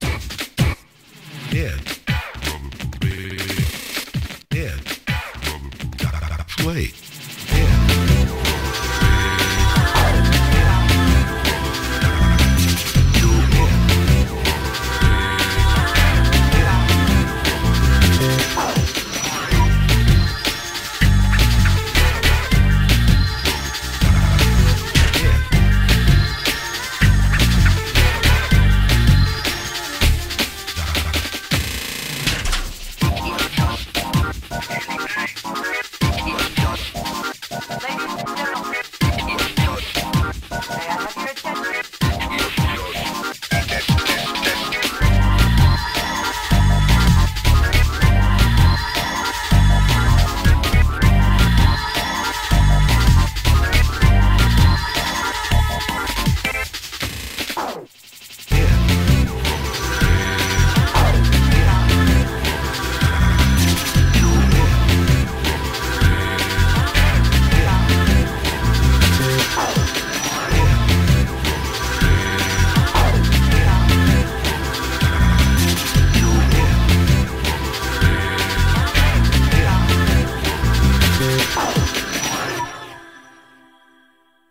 BPM160